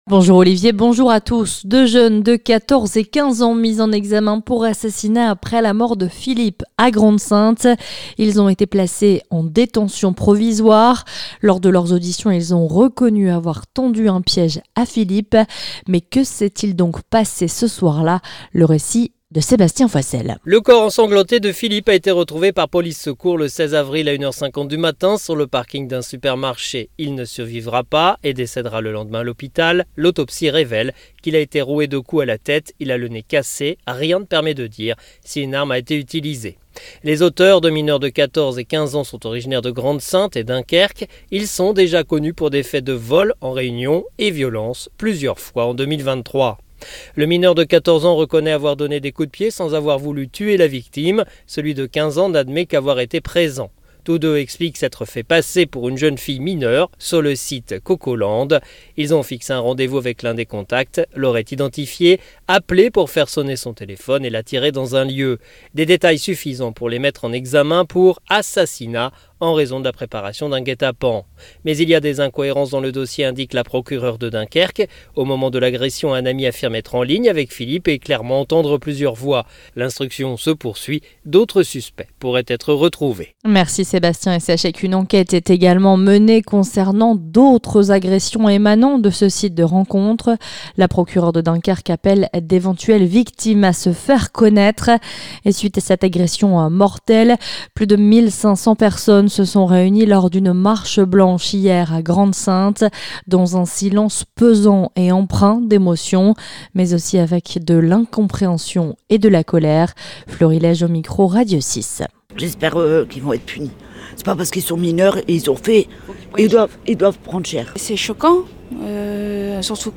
Le journal du samedi 20 avril sur la Côte d'Opale et Picarde